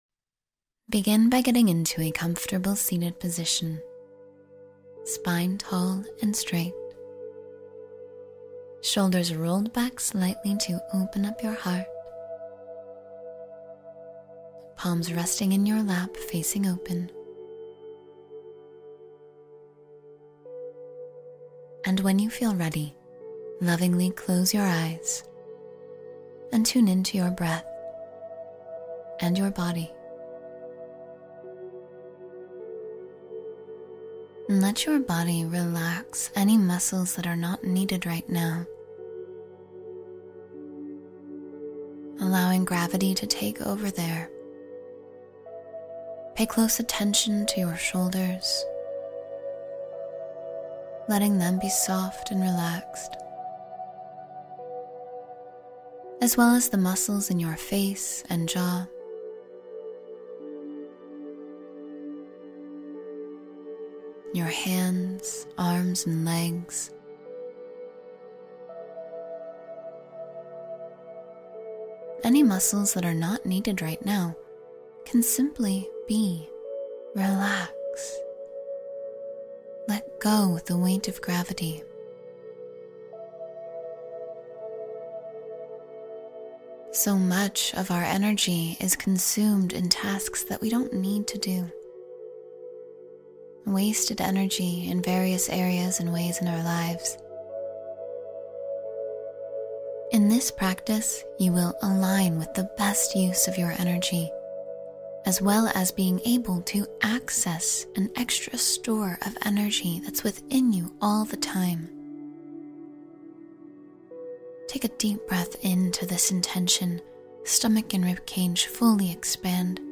Improve and Elevate Your Inner Energy — Meditation for Empowerment